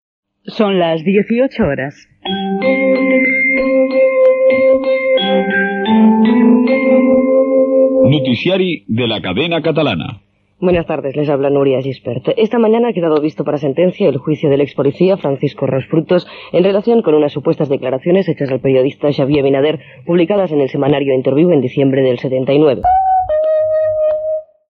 Informatius: titular - Cadena Catalana, 1983